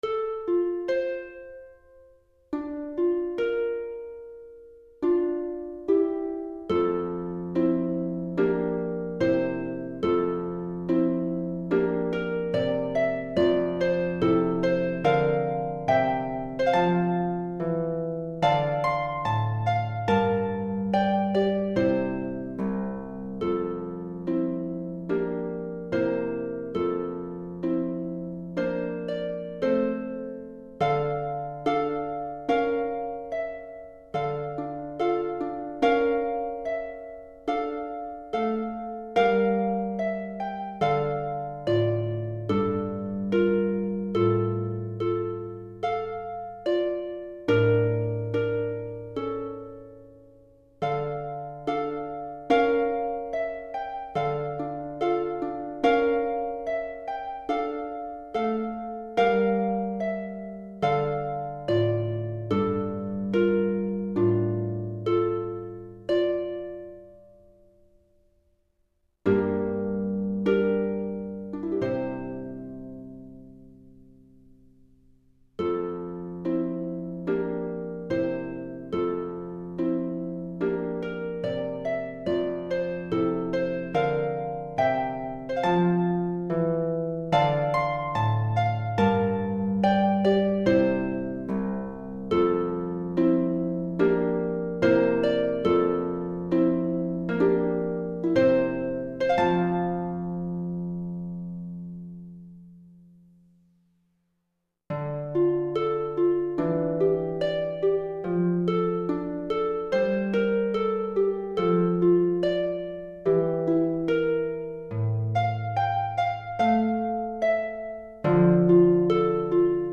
pour harpe solo niveau cycle 1